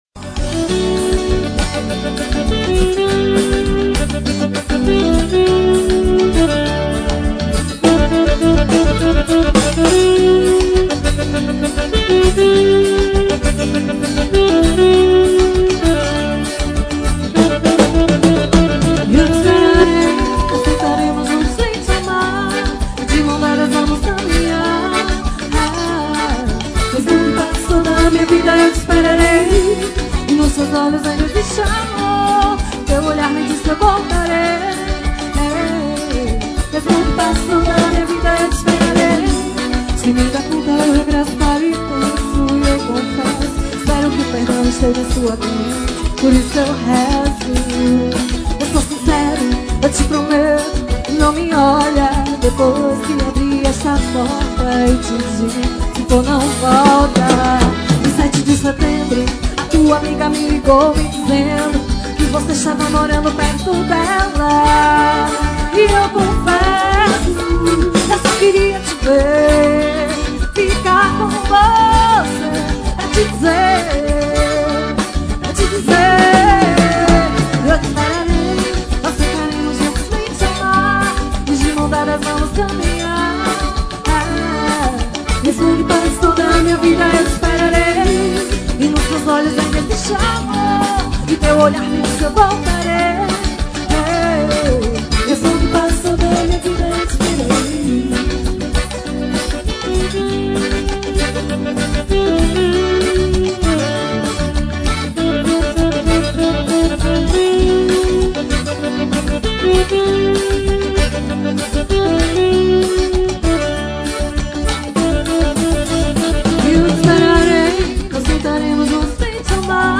Show ao vivo